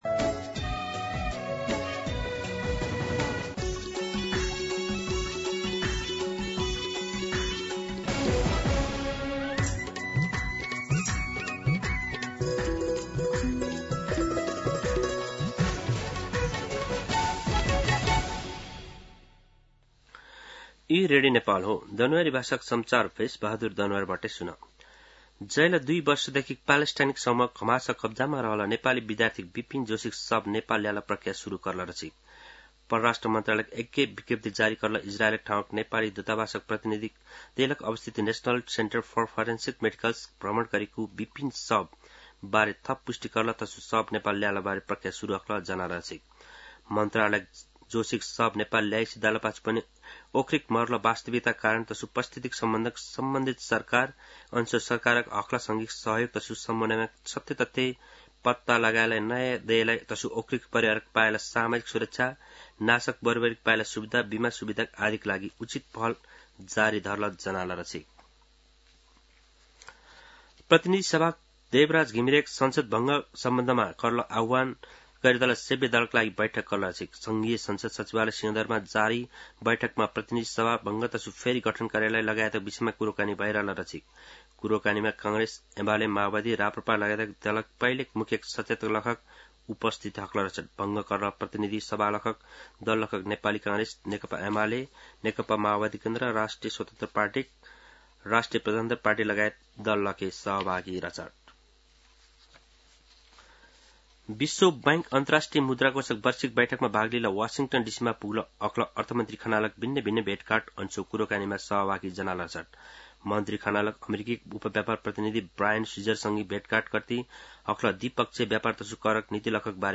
An online outlet of Nepal's national radio broadcaster
दनुवार भाषामा समाचार : २९ असोज , २०८२
Danuwar-News-06-29.mp3